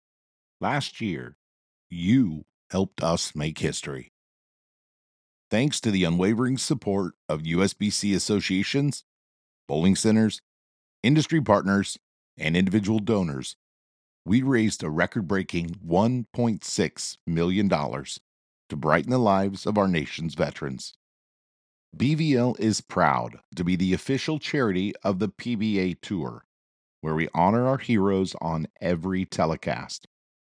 Bowlers to Veterans Link Year in Review Video Narration (Excerpt)